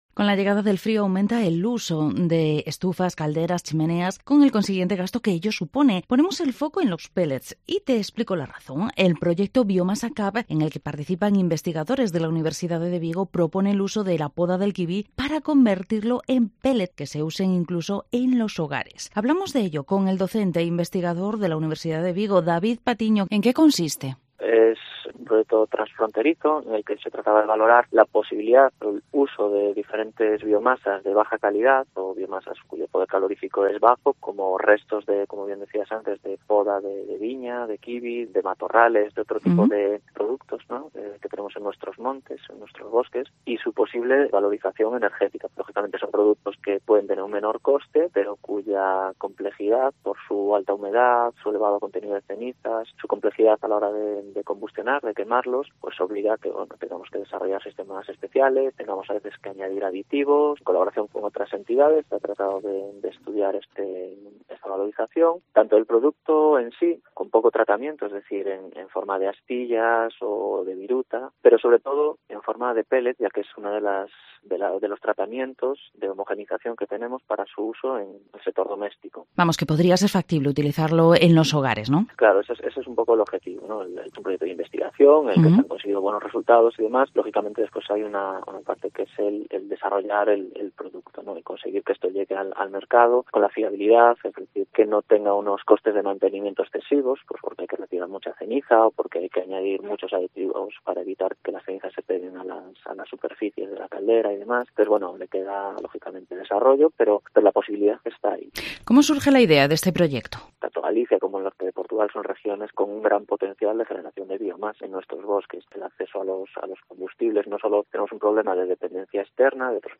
Vigo Entrevista Lograr pellets a partir de la poda de kiwi, así es el Proyecto Biomasa CAP.